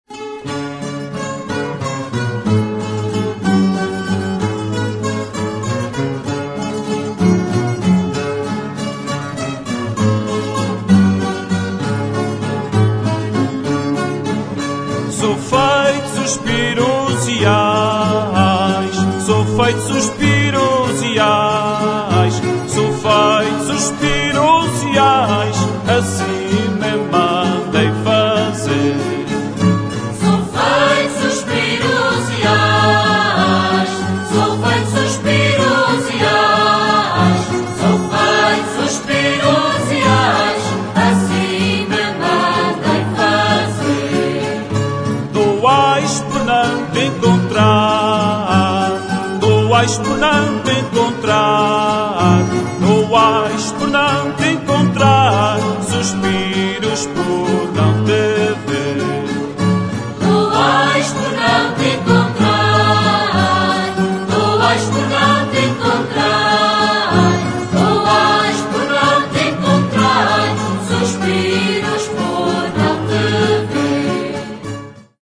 Modas Regionais da Ilha Terceira